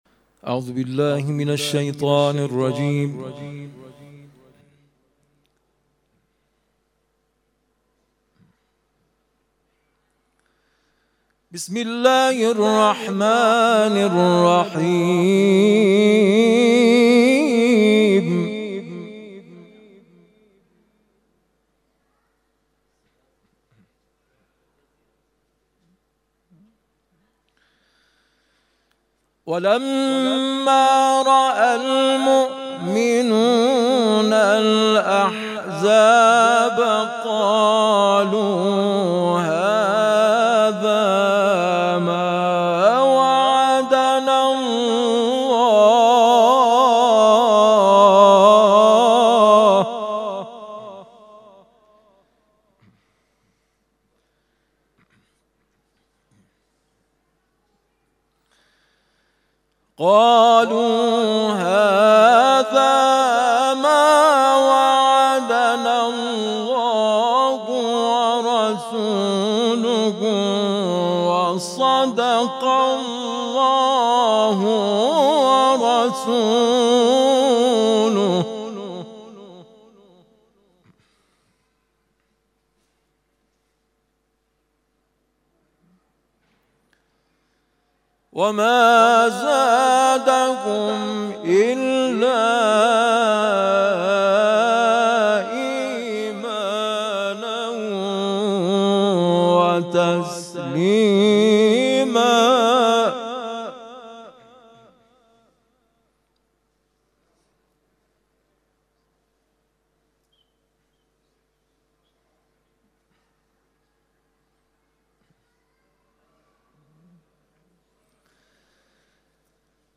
تلاوت مغرب روز جمعه
تلاوت قرآن کریم